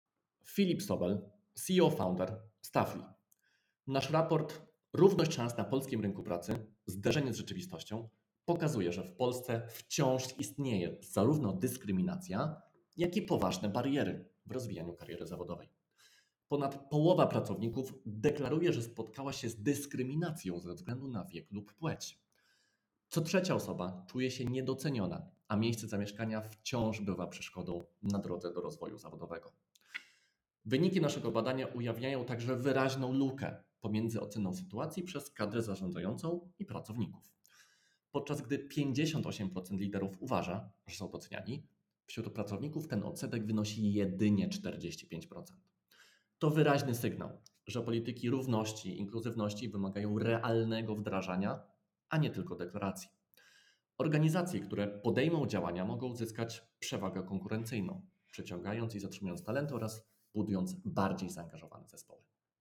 Komentarz do badania